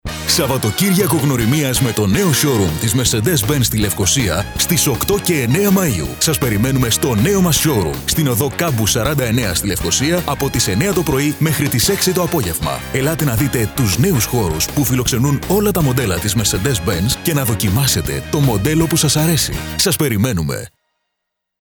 Professional Voice Artist and Voice Coach.
Sprechprobe: Industrie (Muttersprache):